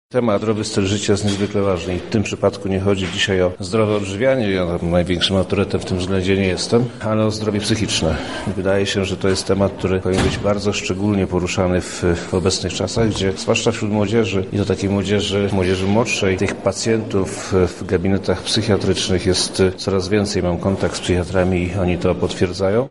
W II liceum ogólnokształcącym imienia Jana Zamoyskiego w Lublinie, odbyło się sympozjum pod tytułem „Zdrowy Styl Życia”.
W sympozjum wziął także udział były uczeń liceum wojewoda lubelski Przemysław Czarnek: